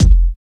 62 KICK.wav